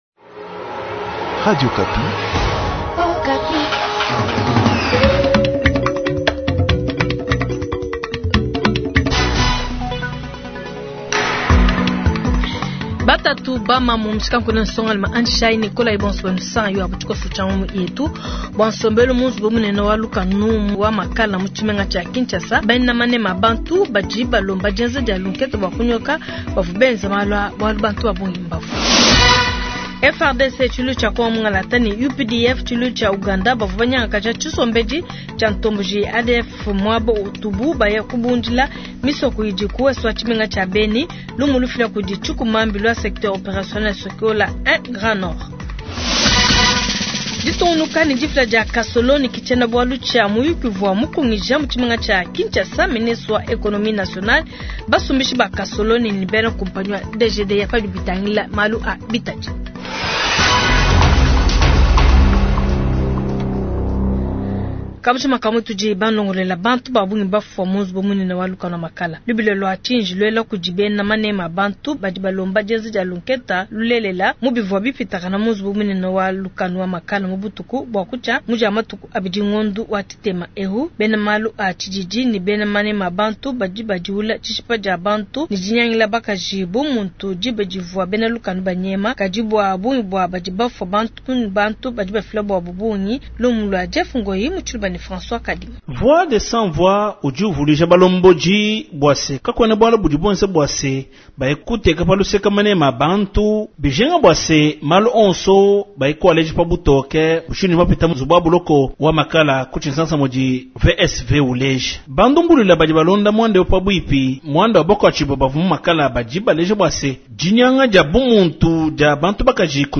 Journal matin
KIN : Témoignage d’un voisin de la prison de Makala